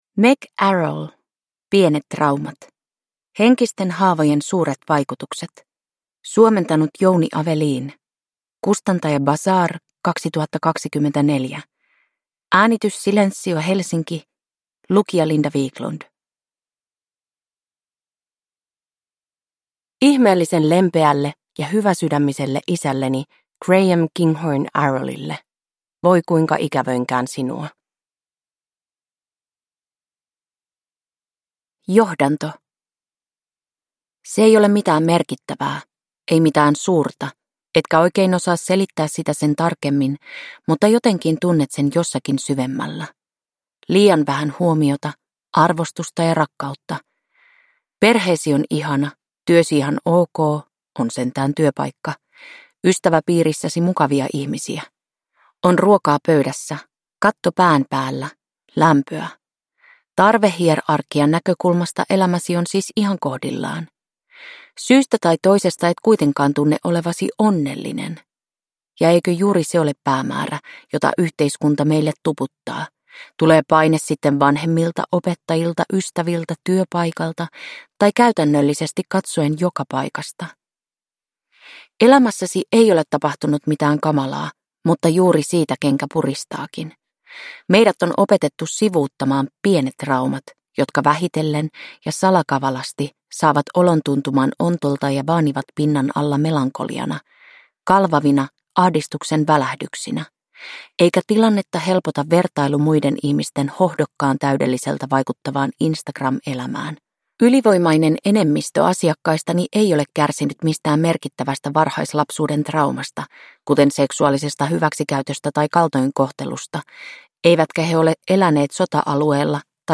Pienet traumat – Ljudbok